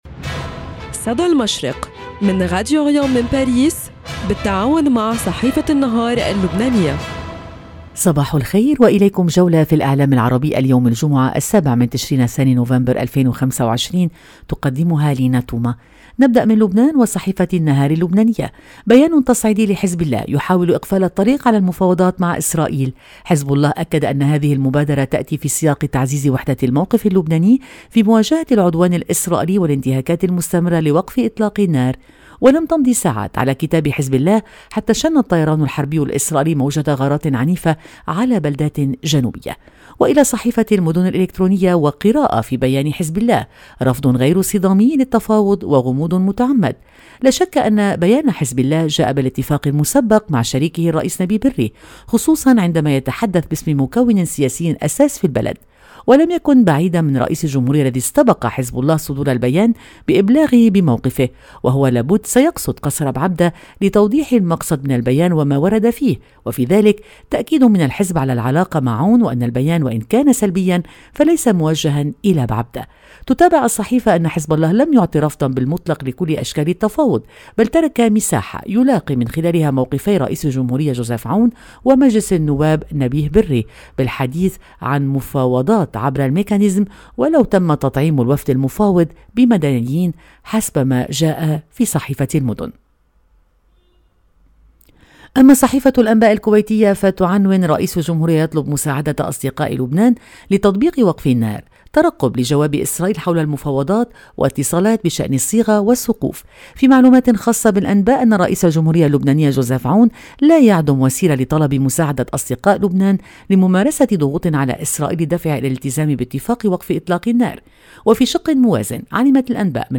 صدى المشرق – نافذتكم اليومية على إعلام الشرق، كل صباح في تعاون بين راديو أوريان إذاعة الشرق من باريس مع جريدة النهار اللبنانية، نستعرض فيها أبرز ما جاء في صحف ومواقع الشرق الأوسط والخليج العربي من تحليلات مواقف وأخبار،  لنرصد لكم نبض المنطقة ونحلل المشهد الإعلامي اليومي.